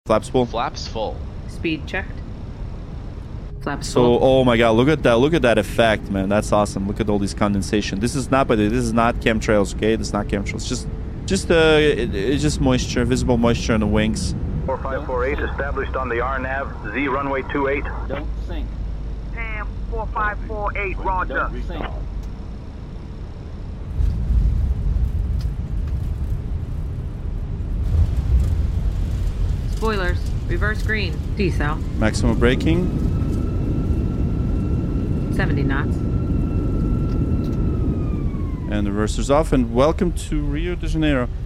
Watch this LATAM A319 nail the dangerous 20L approach into Rio’s Santos Dumont - completely hand-flown, live using the Fenix A319 in Microsoft Flight Simulator.